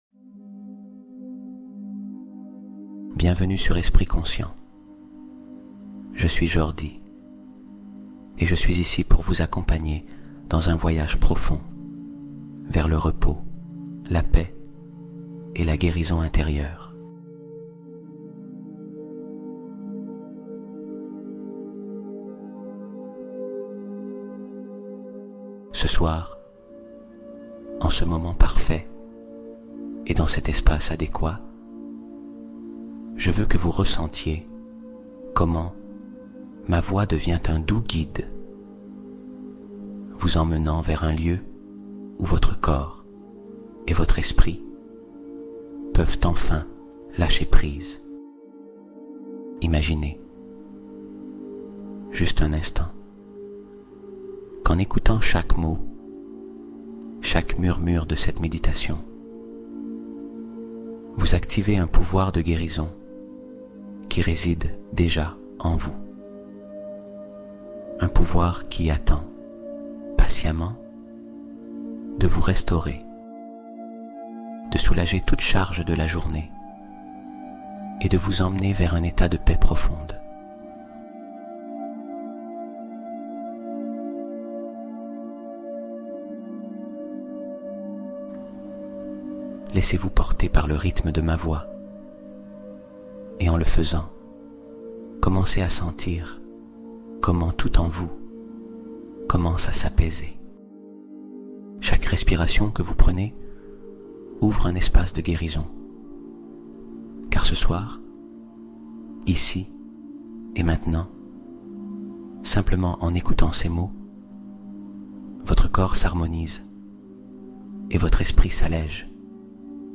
Réparation de l’Âme : Méditation nocturne pour une guérison intérieure